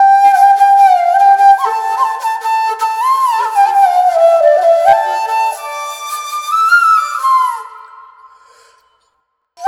Bansuri.wav